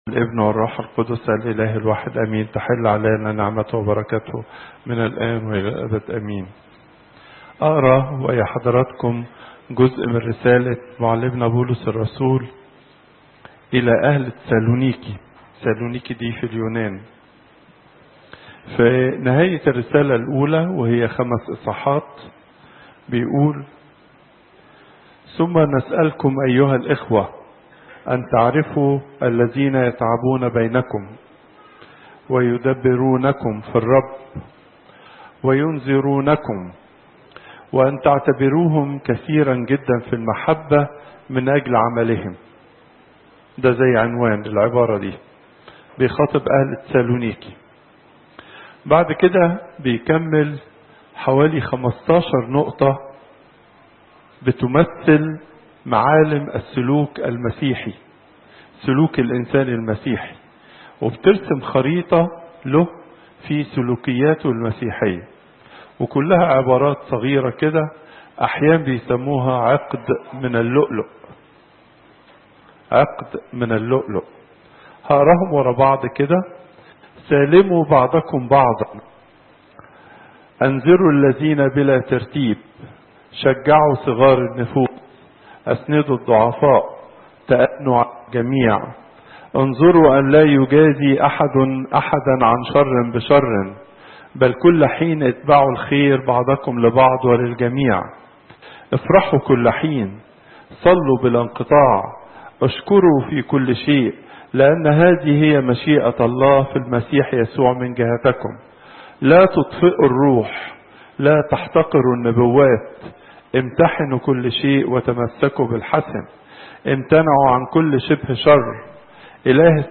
Popup Player تحميل الصوت البابا تواضروس الثانى الأربعاء، 07 يونيو 2017 43:30 المحاضرة الأسبوعية لقداسة البابا تواضروس الثاني الزيارات: 1028